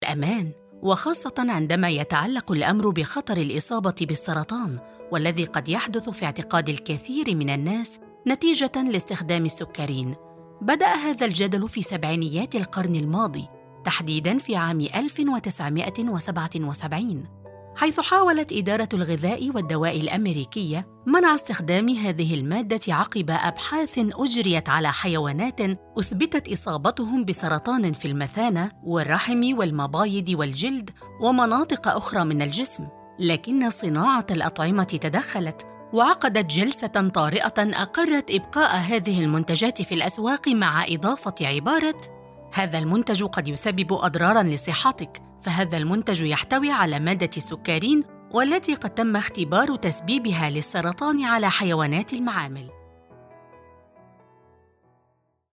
Narración médica
Micrófono: Rode NT1-A
Estudio: Estudio casero con tratamiento profesional para una acústica óptima